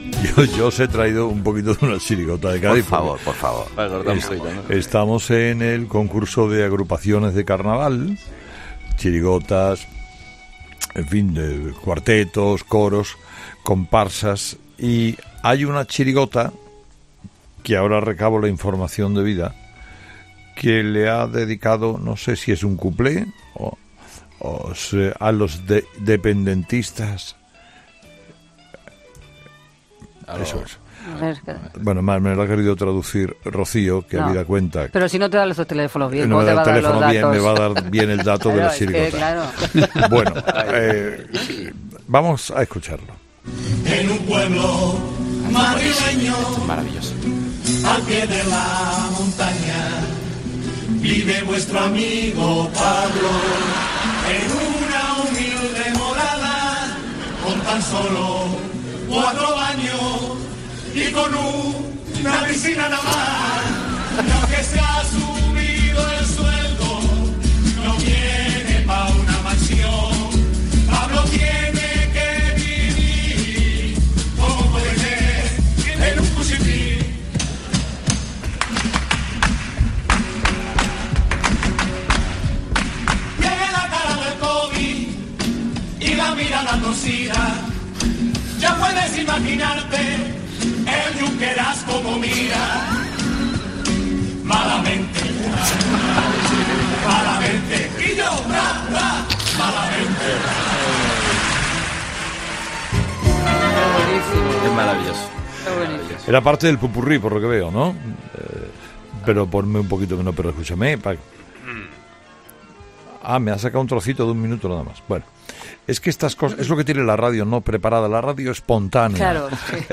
Herrera se parte de risa con la chirigota de Cádiz sobre la casa de Iglesias en Galapagar
La chirigota sevillana 'Los dependentistas' hicieron las delicias del Teatro Falla en el COAC (Concurso Oficial de Agrupaciones Carnavalescas de Cádiz).
Esta versión de la canción de la serie italiana Marco la cantaron en el popurrí.
Chirigota Los dependentistas